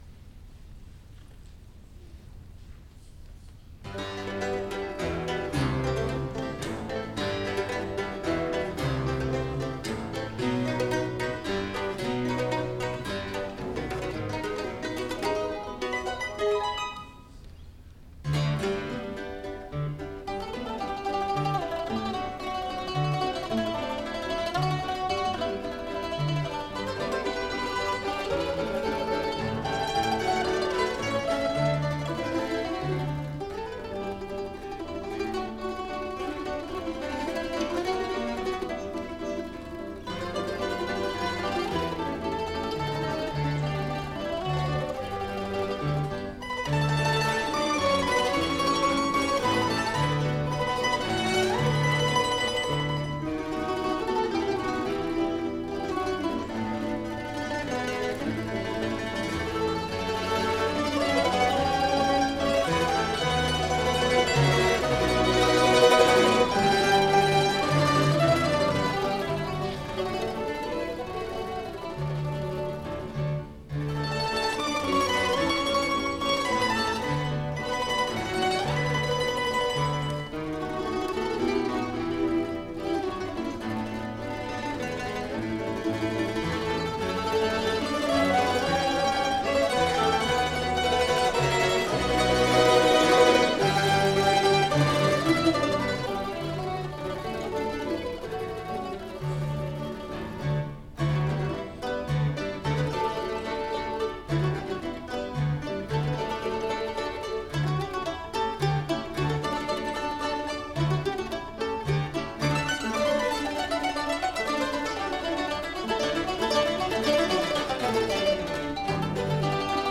ソロとアンサンブルの夕べ(1980.7.2 府立文化芸術会館)
マンドリン アンサンブル